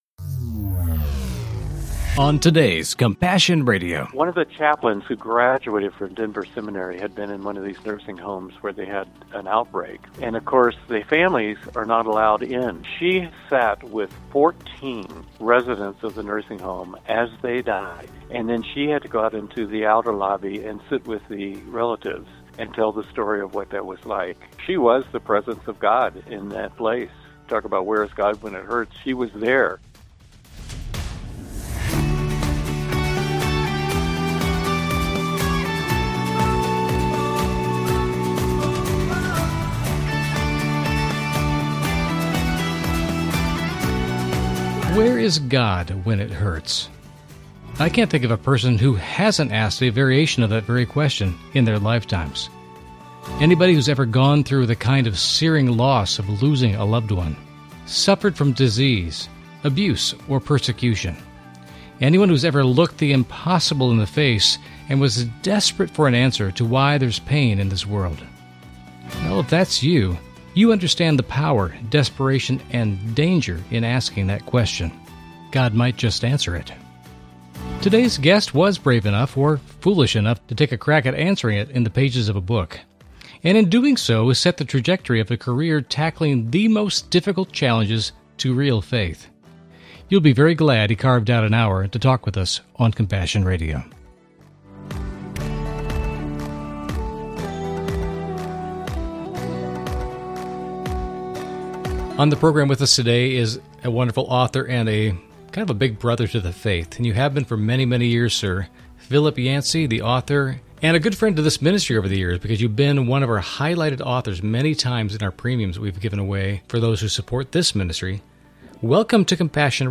Genre: Christian News Teaching & Talk.